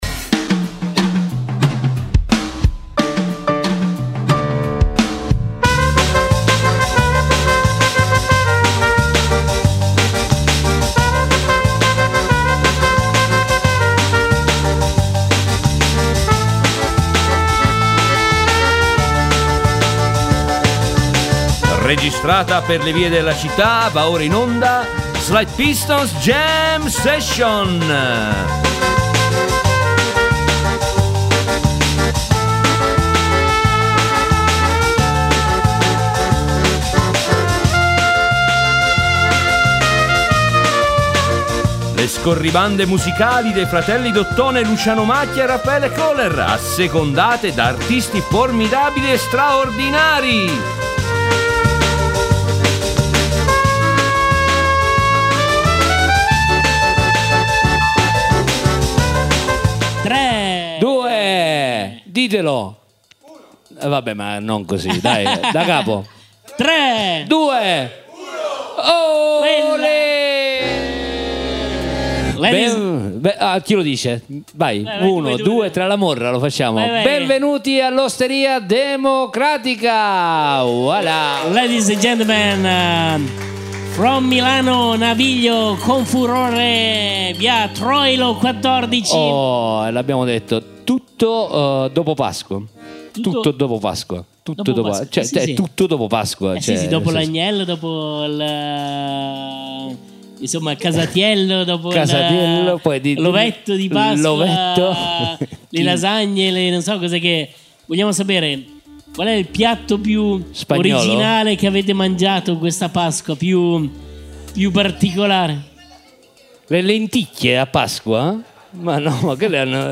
In onda le scorribande musicali dei due suonatori d’ottone in giro per la città, assecondate da artisti formidabili e straordinari.